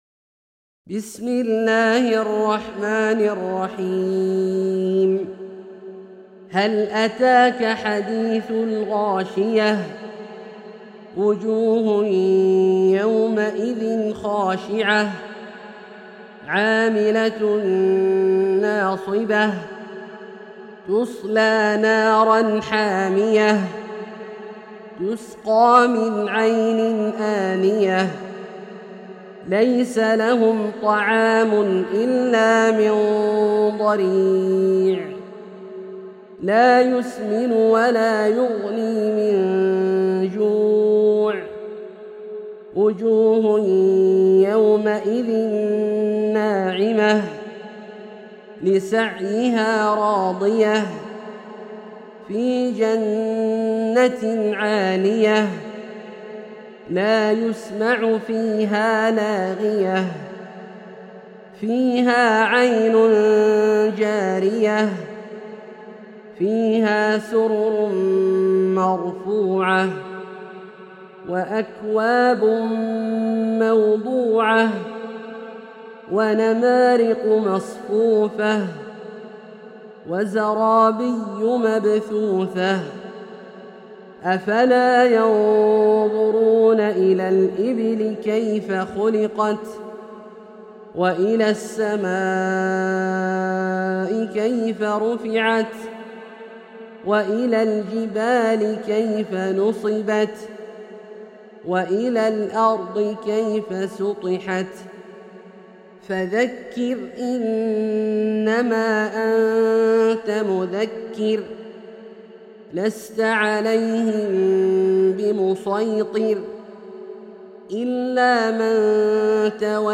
سورة الغاشية - برواية الدوري عن أبي عمرو البصري > مصحف برواية الدوري عن أبي عمرو البصري > المصحف - تلاوات عبدالله الجهني